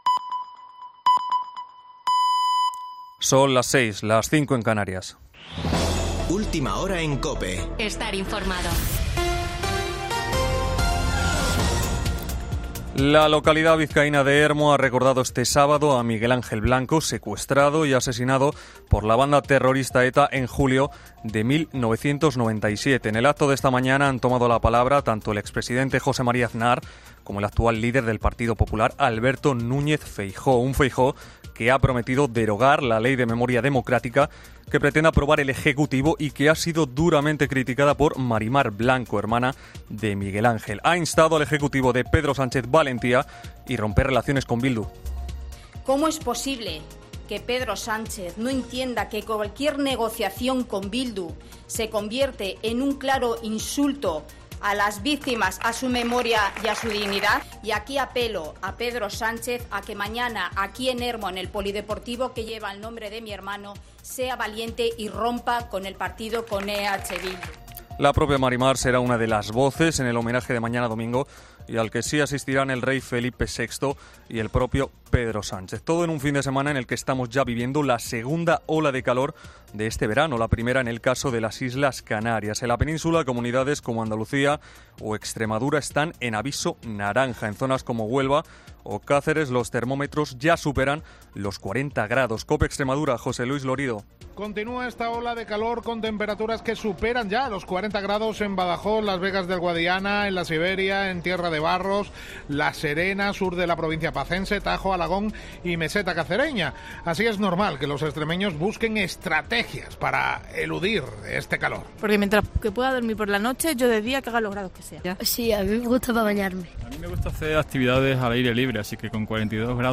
Boletín de noticias de COPE del 9 de julio de 2022 a las 18.00 horas